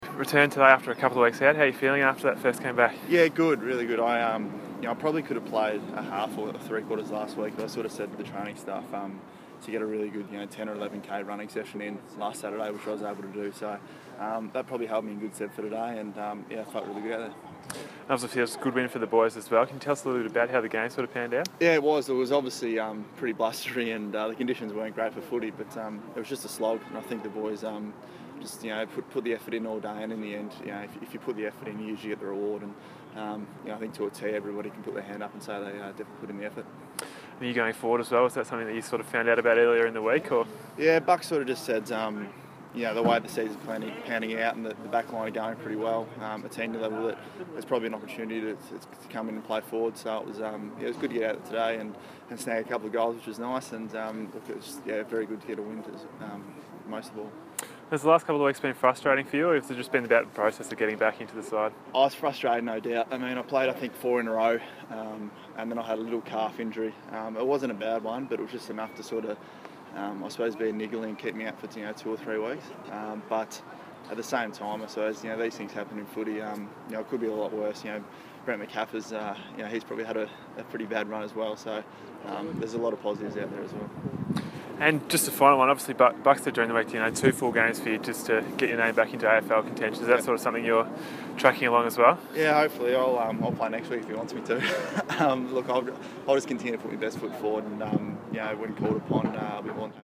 VFL Interview: Ben Reid, R15 2015